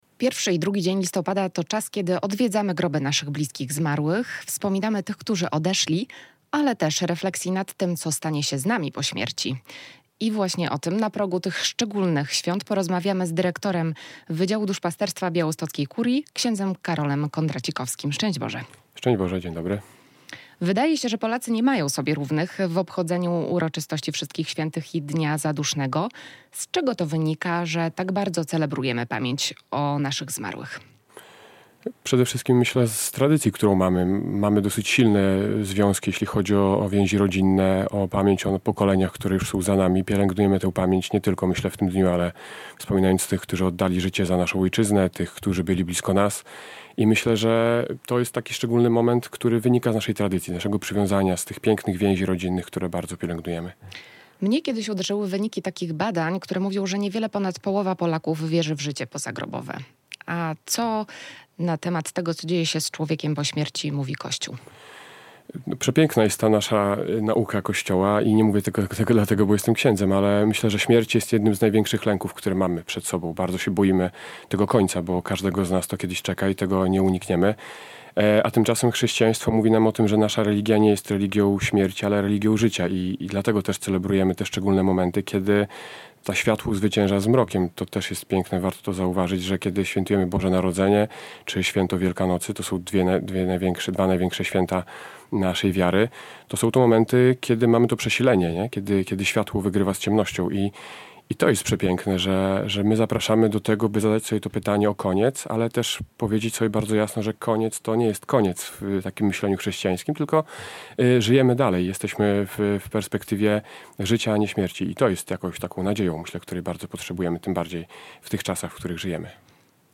Radio Białystok | Gość